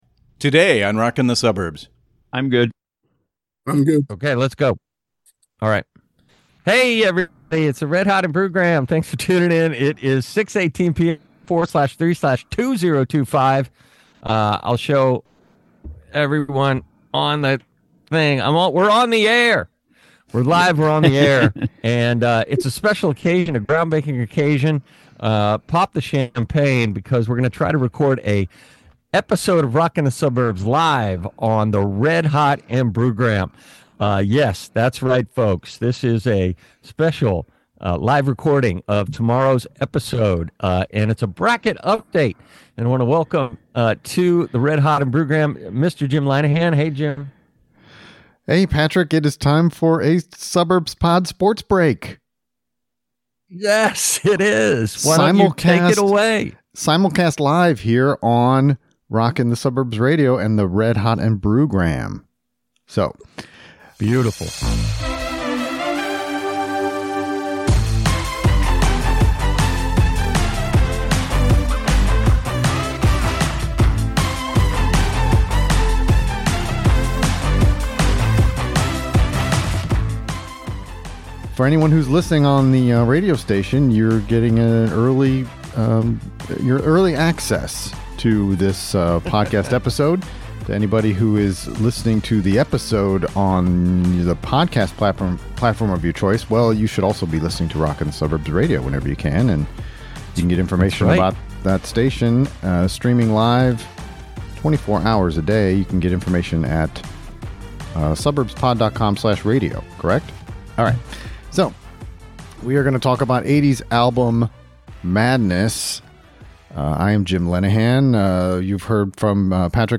It's time for another Suburbs Pod Sports Break! This time, we are simulcast on Rockin' the Suburbs Radio!